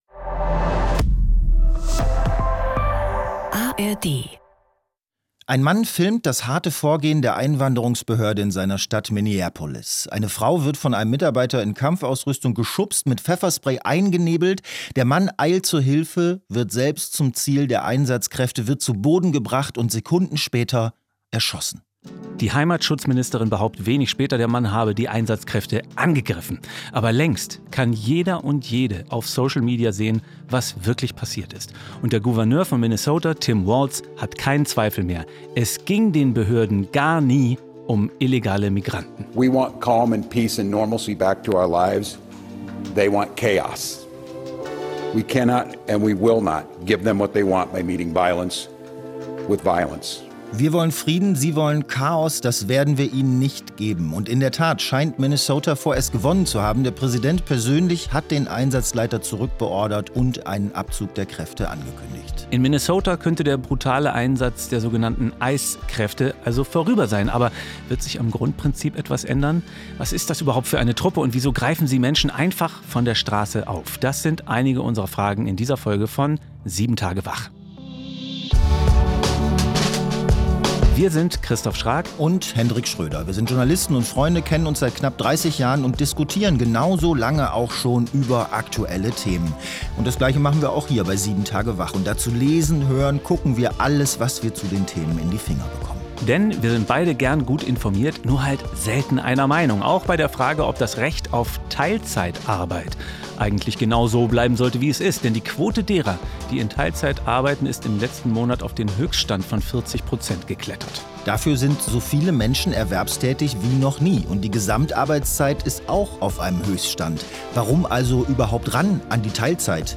Zwei Freunde, zwei Meinungen, ein News-Podcast: